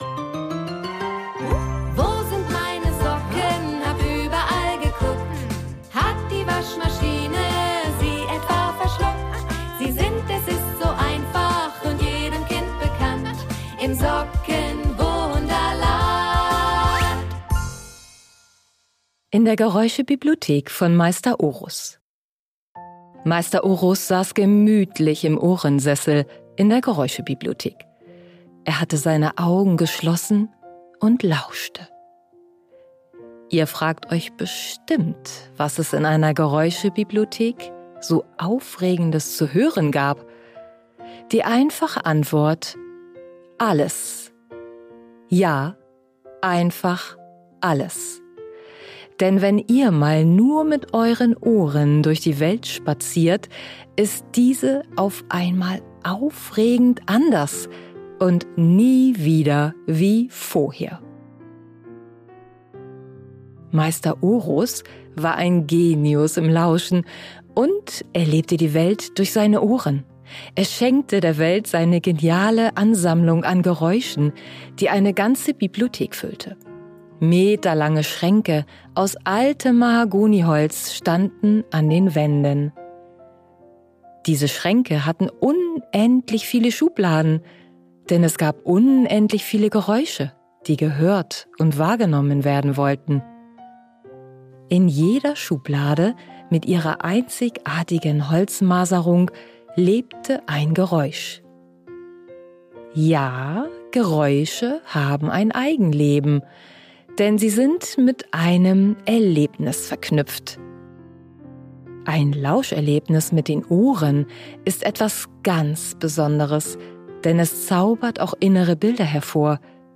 In der Geräusche-Bibliothek von Meister Ohrus ~ Im Sockenwunderland - Lausch-Geschichten für Kinderherzen Podcast